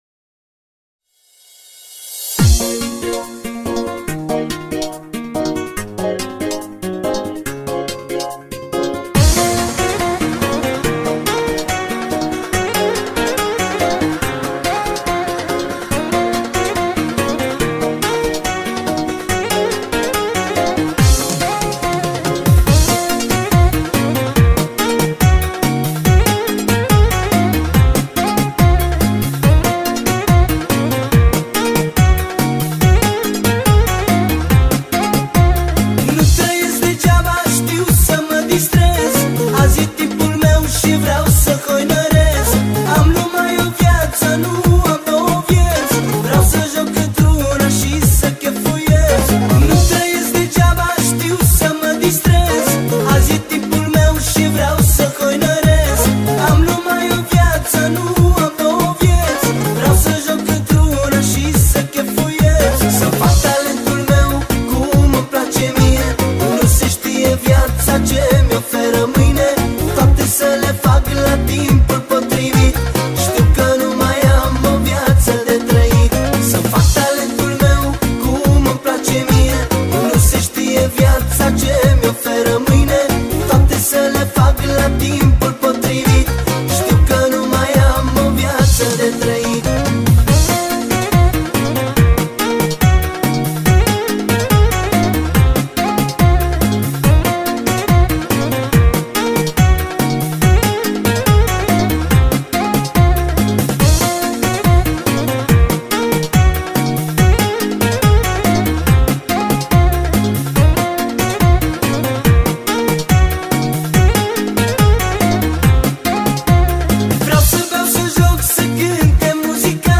Categoria: Manele New-Live